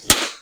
partypopper.wav